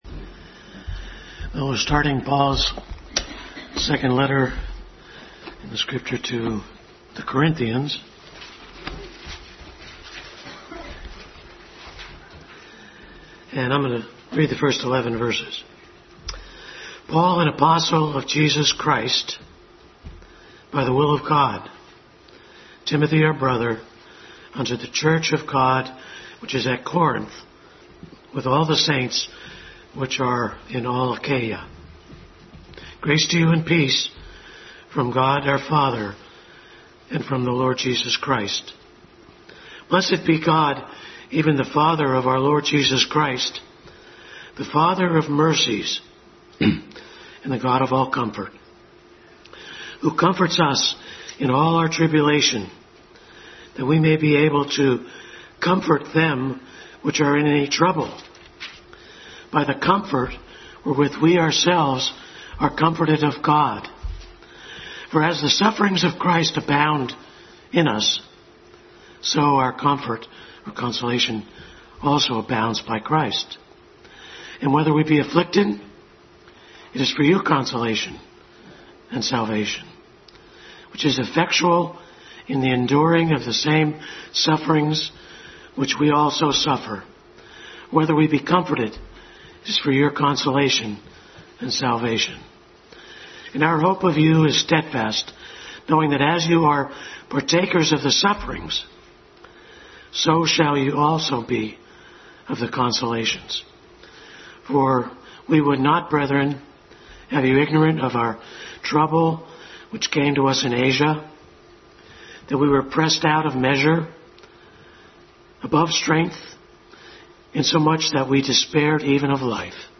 Adult Sunday School Class study of 2 Corinthians.
Acts 14:22 Service Type: Sunday School Adult Sunday School Class study of 2 Corinthians.